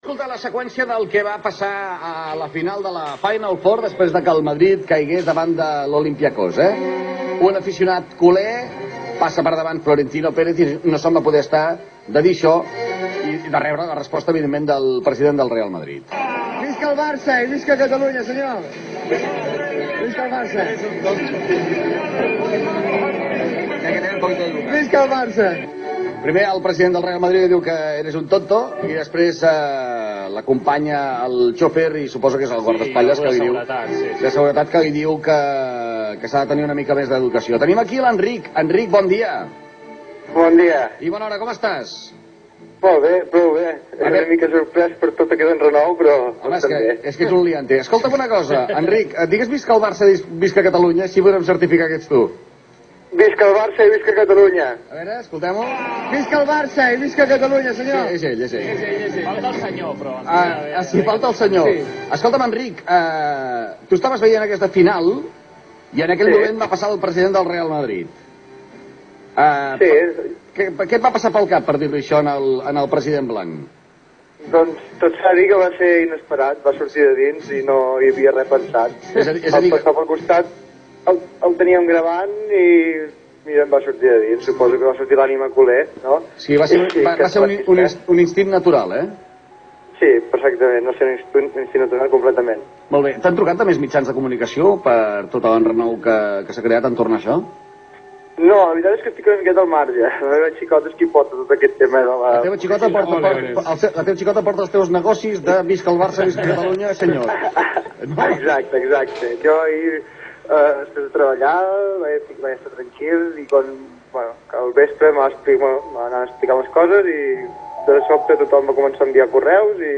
radiointerview.mp3